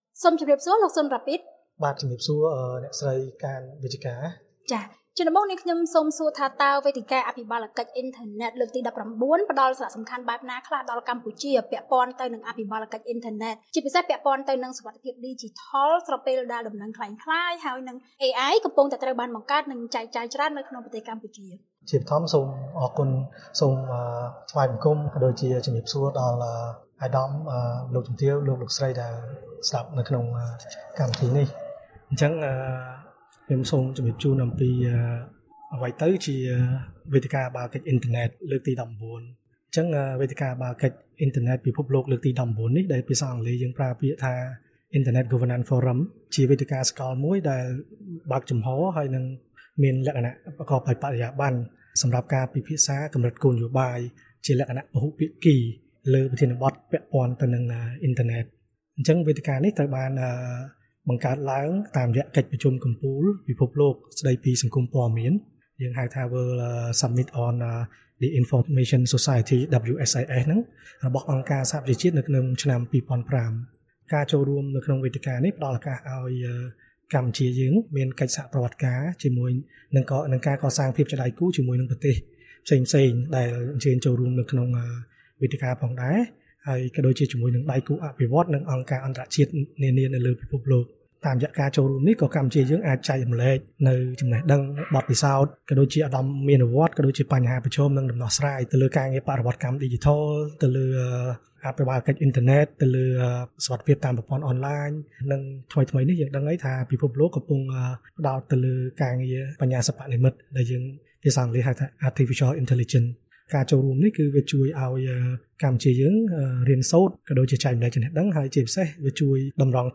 បទសម្ភាសន៍ VOA៖ កម្ពុជាពង្រឹងអភិបាលកិច្ចអ៊ីនធឺណិតដើម្បីបង្កើនការប្រើប្រាស់និងសុវត្ថិភាព